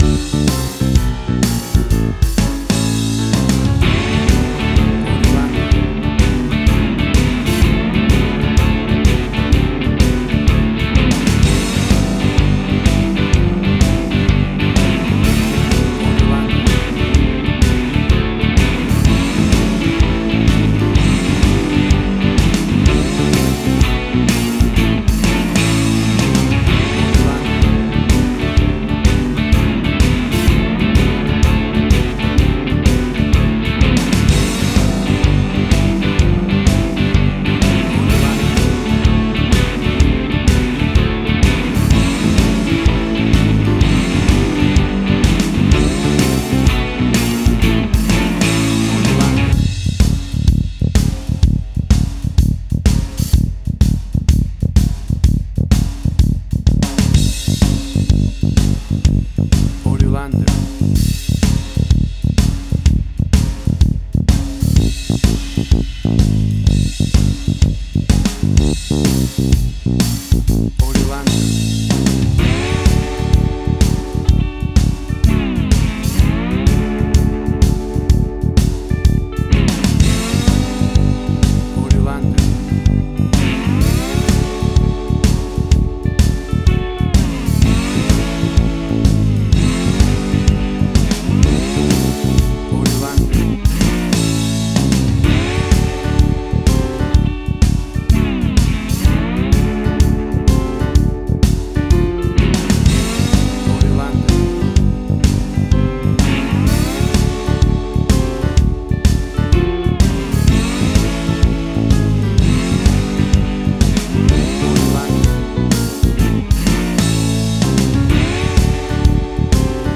classic piece of vintage raw blues music
Tempo (BPM): 125